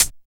18 HAT    -R.wav